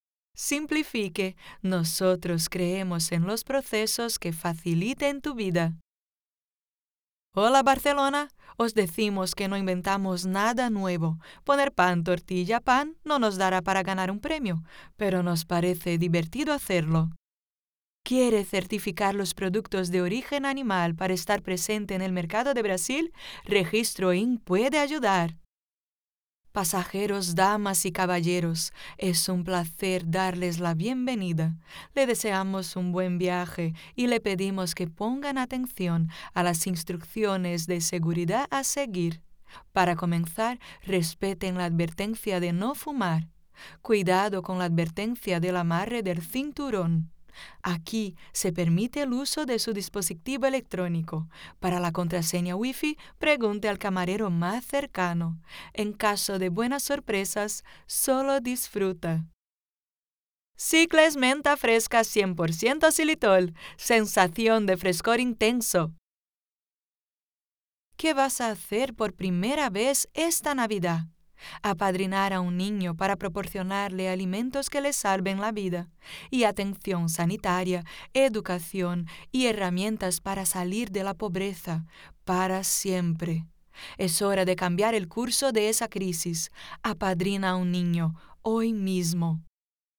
Locutores Profissionais, locução publicitária, locutores comerciais.
Español Acento: Neutral, Carioca, Paulistano Femenino Hola